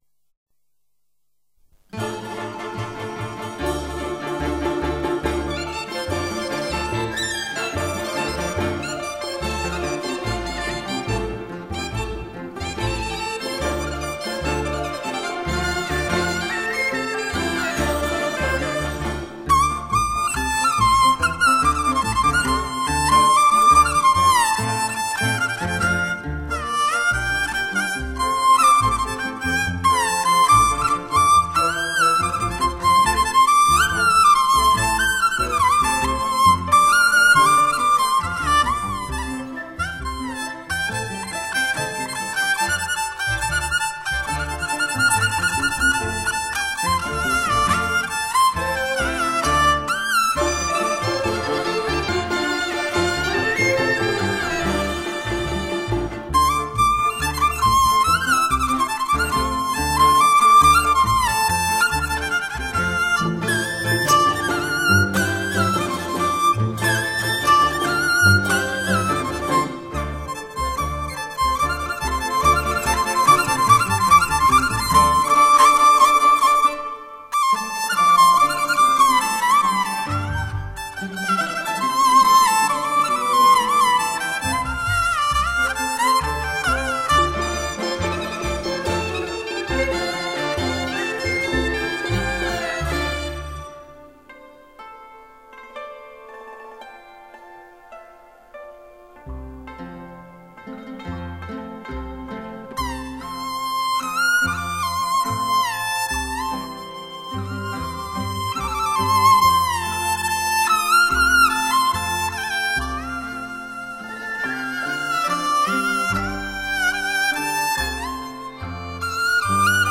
“祝大家开年行好运”世纪名曲-音乐极品(中国弦乐
通常我们看到的二胡、高胡、板胡、中胡、京胡便是这类乐器。
坠胡
马头琴
二胡
胡琴五重奏